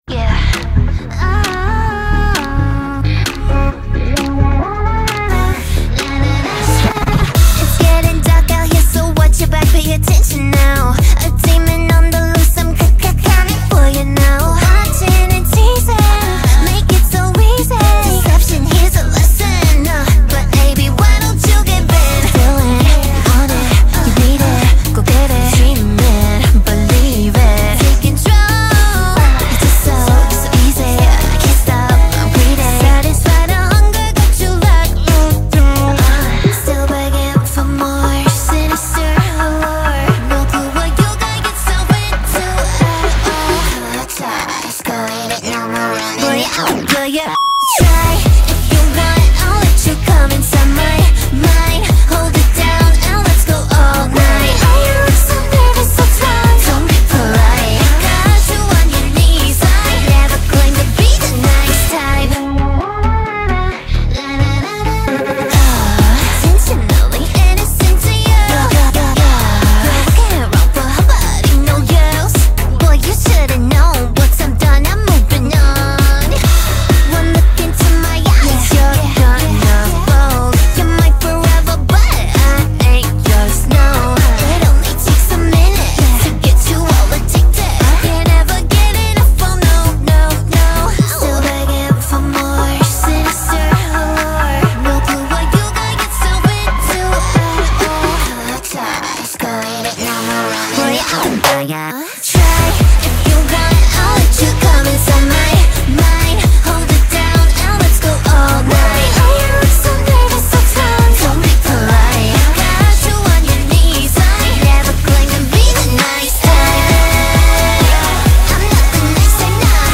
BPM132
Audio QualityCut From Video